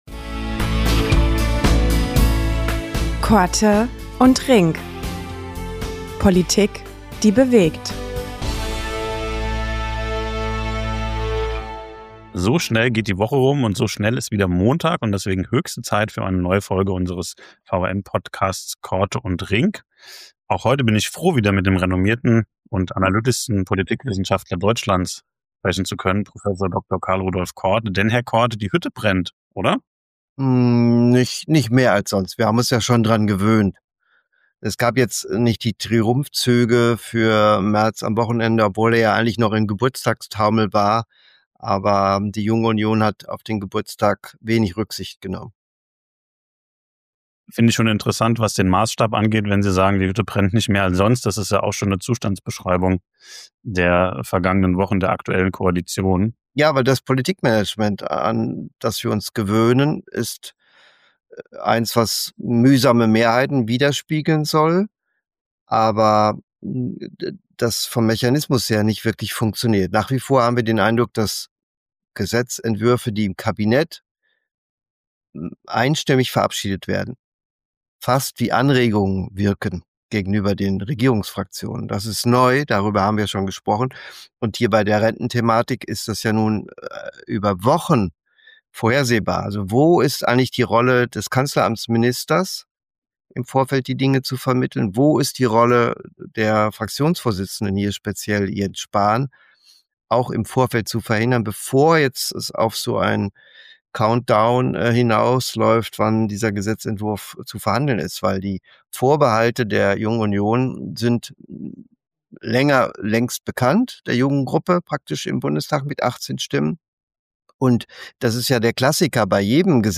Ein Gespräch über Sicherheit, Generationengerechtigkeit und politische Glaubwürdigkeit – in einer Gesellschaft, die ihre Zukunft neu verhandeln muss.